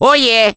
Below lies a collection of voice clips and sound effects from the first in the Mario Galaxy series!